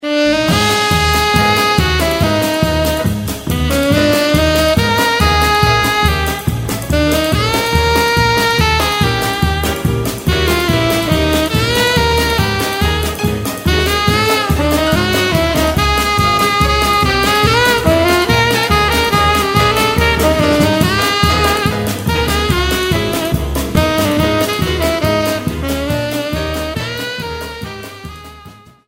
• Качество: 128, Stereo
инструментальные
праздничные
джаз